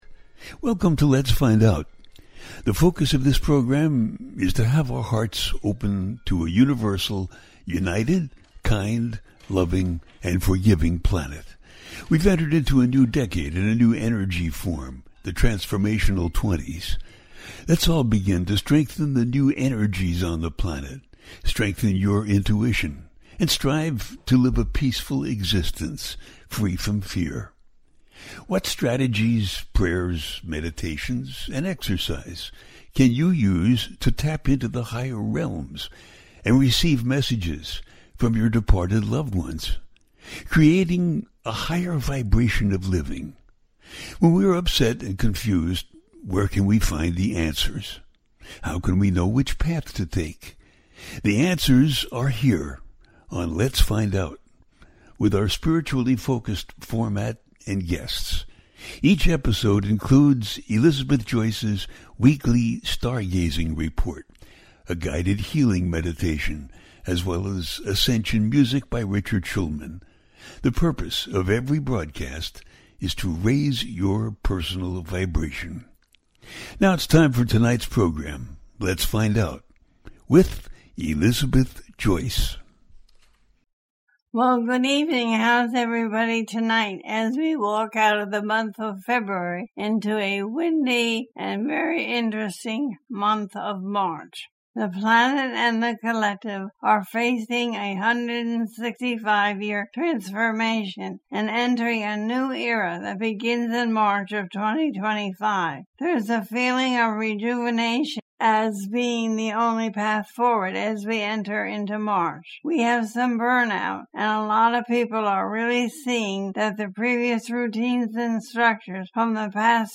The Transformation Coming After March 2025 - A teaching show